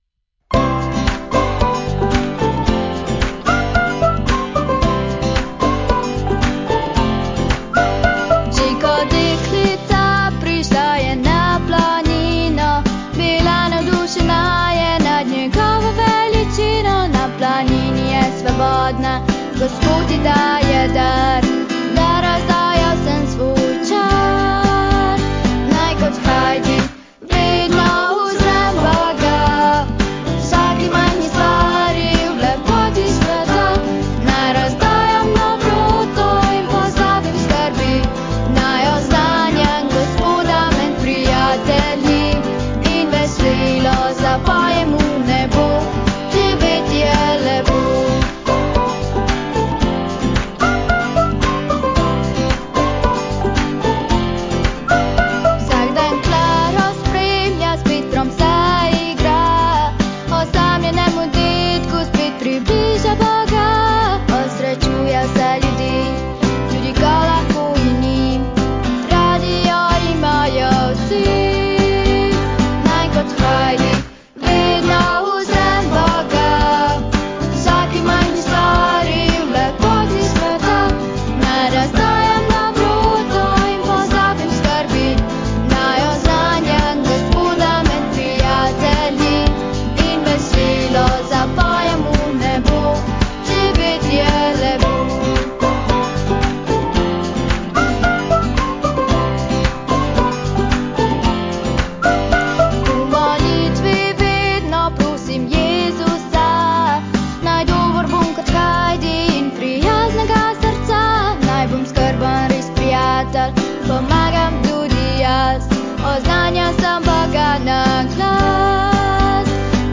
Vokal
Spremljevalni vokal